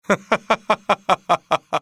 vs_fScarabx_haha.wav